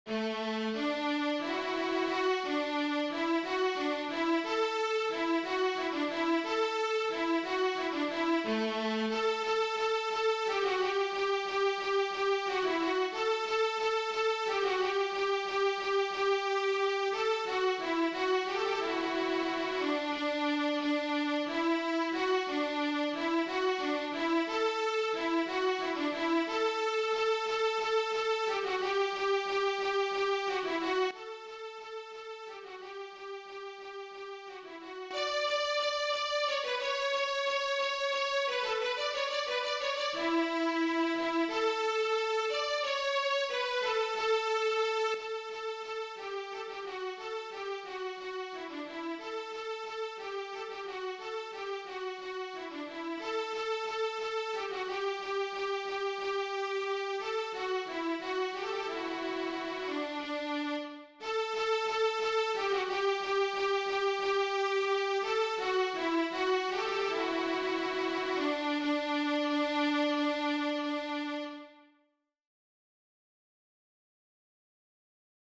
DIGITAL SHEET MUSIC - VIOLA SOLO